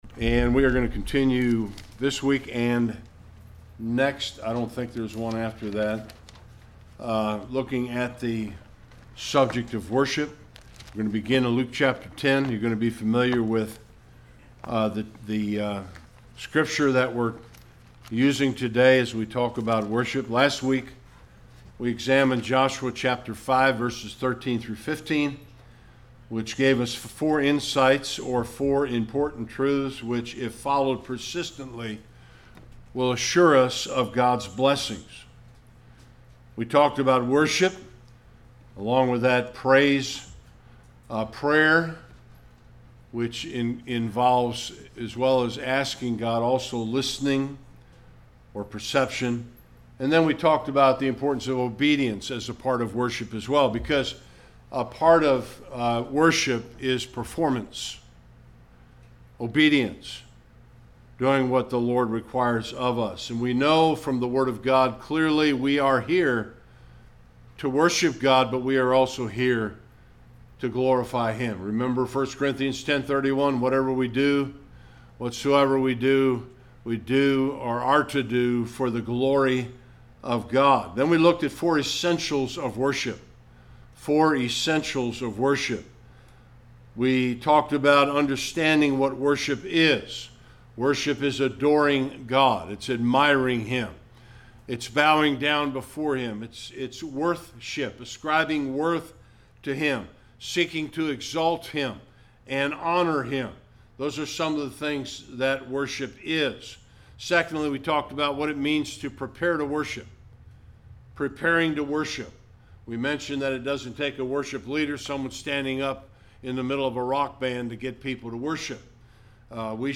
worship Passage: Luke 10: 38-42; John 11: 20-22; 12: 2-7. Service Type: Sunday Worship Topics: Worship « Acts 21: 18-40 Acts 22:1-21 » Submit a Comment Cancel reply Your email address will not be published.